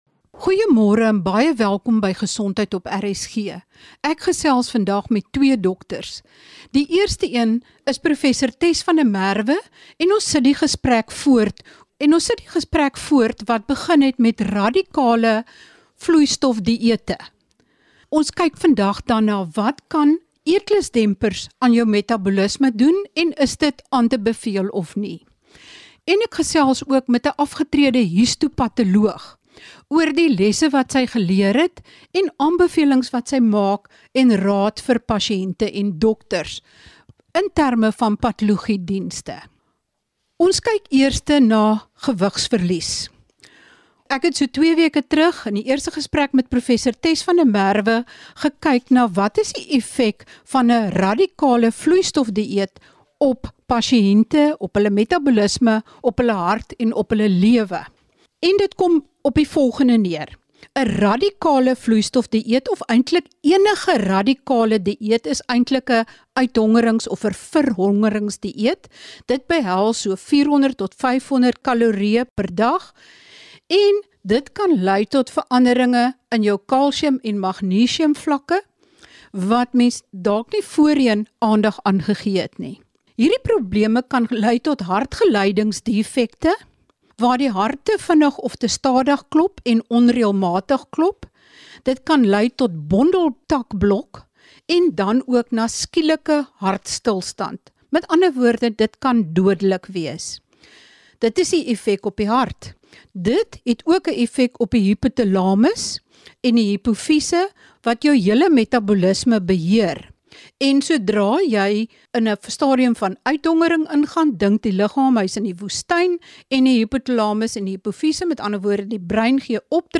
En ’n histopatoloog gee ons ‘n kykie in ’n patologielaboratorium.